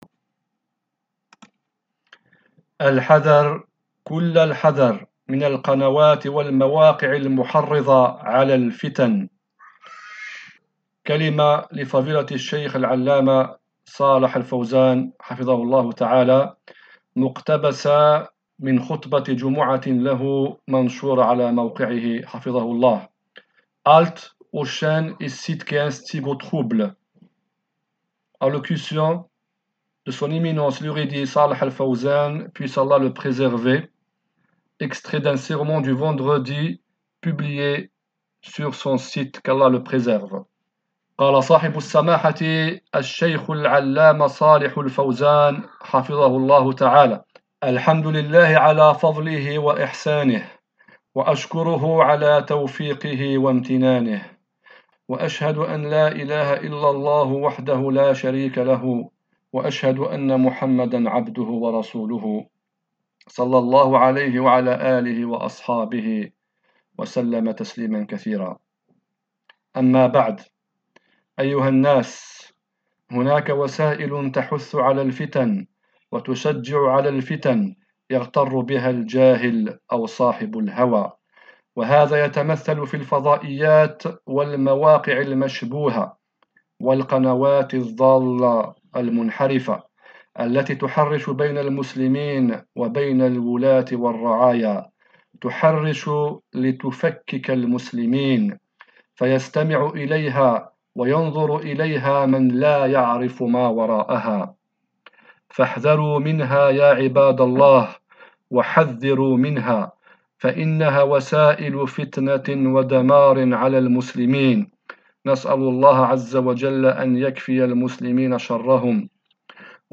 Allocution de son Eminence, l’érudit
Traduite en français et présentée par